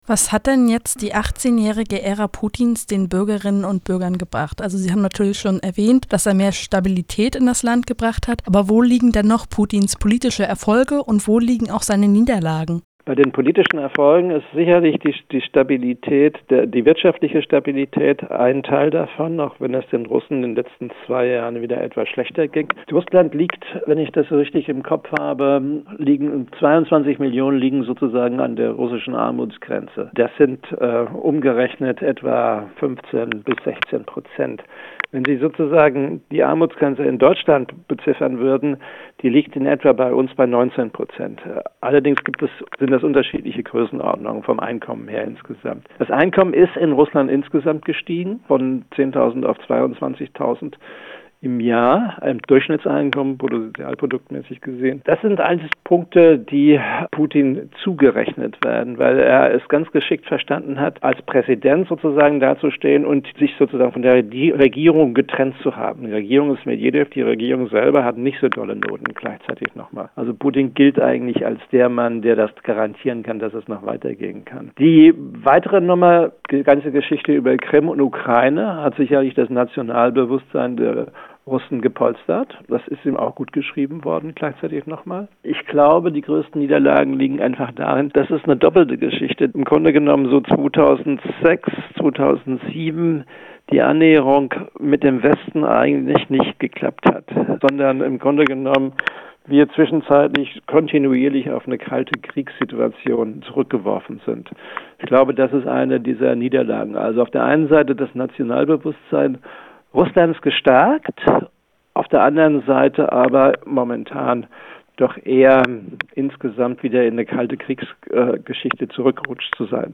Zur Wiederwahl: Interview mit Putin-Experte